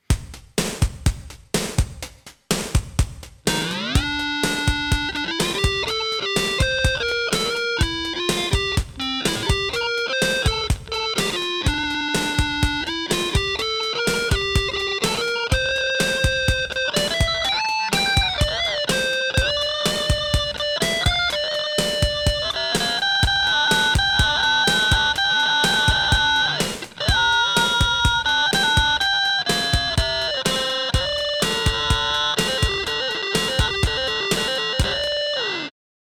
Another very cool feature is its Sample Rate Tuning Mode where the pedal analyzes your input signal in real-time and sets the sample rate to match the pitch of your signal, or a set interval above your signal.
Sample Rate Tuning Mode
MainframeBit-Crusher-Sample-Rate-Tuning-Mode.mp3